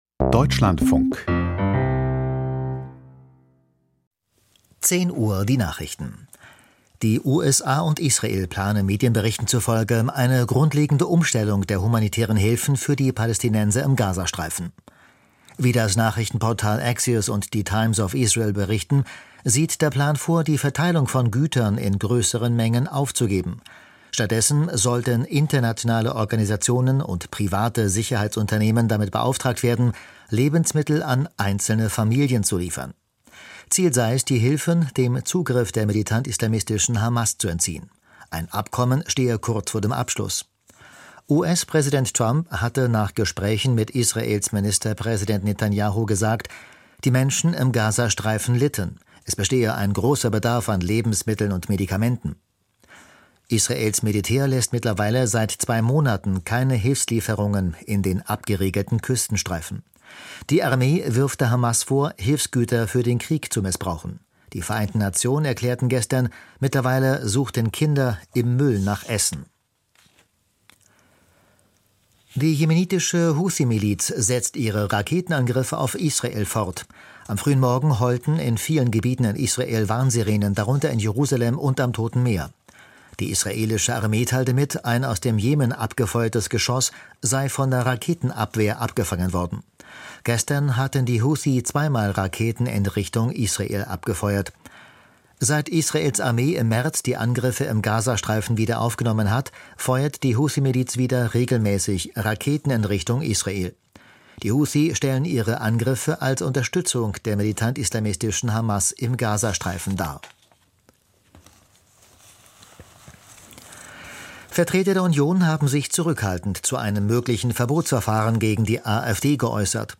Die Deutschlandfunk-Nachrichten vom 03.05.2025, 10:00 Uhr